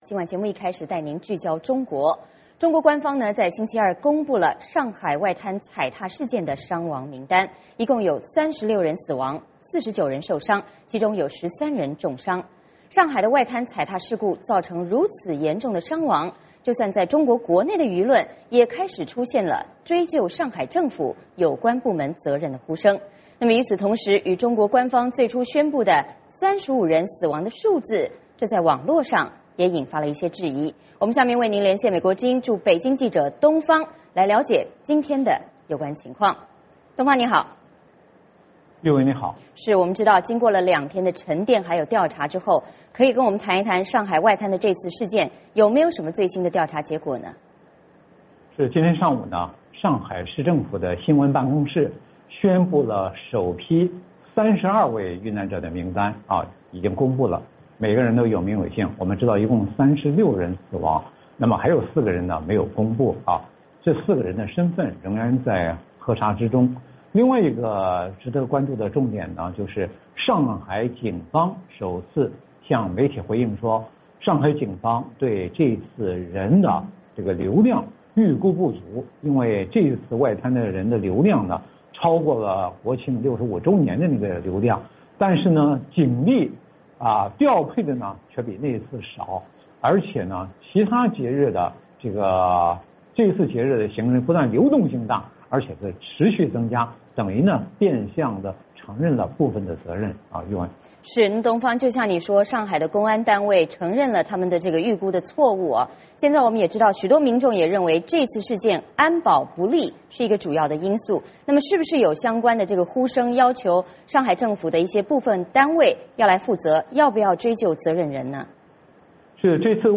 VOA连线：上海外滩踩踏事故，要求惩处官员呼声高